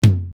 LM-1_Tom_1_TL.wav